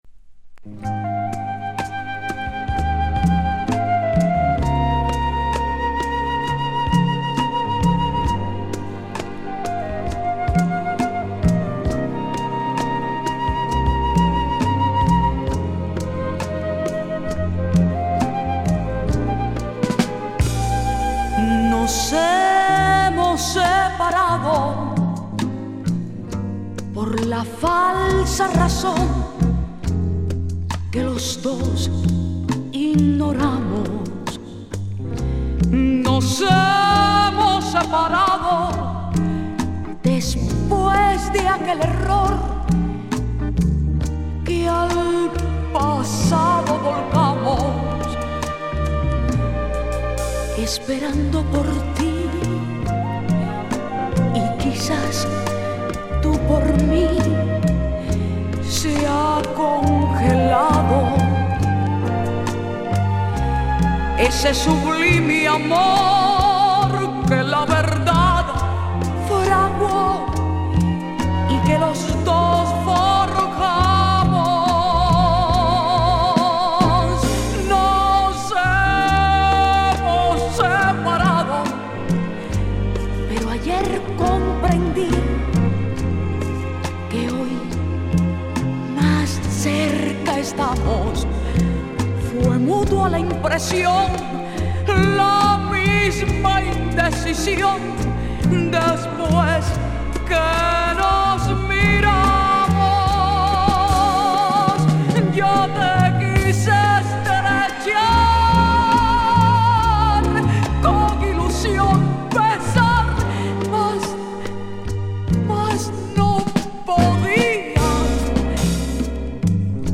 1970年から活動を始めたキューバの女性シンガー
往年のボレロ/フィーリンの名曲カバーも収録